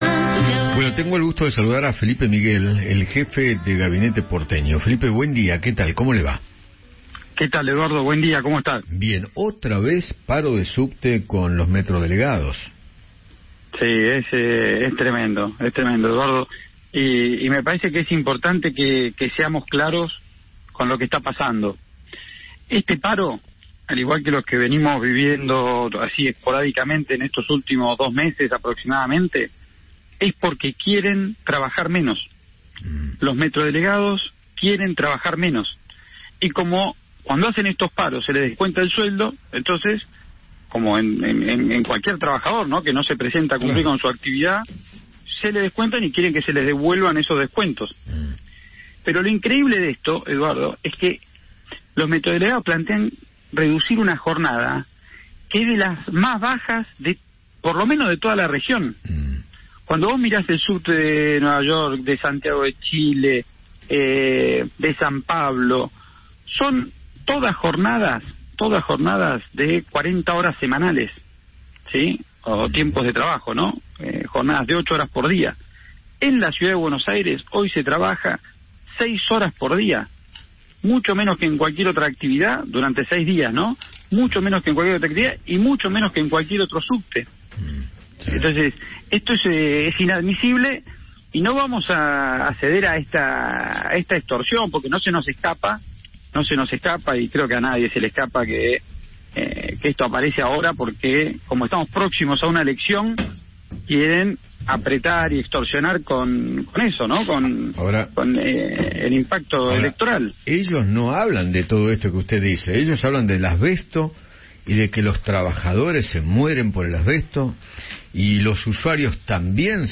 Felipe Miguel, jefe de Gabinete porteño, conversó con Eduardo Feinmann sobre el nuevo paro de subtes y acusó a los metrodelegados de querer “trabajar menos”.